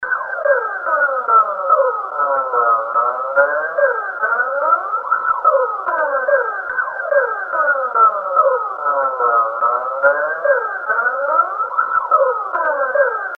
Spook Loop.wav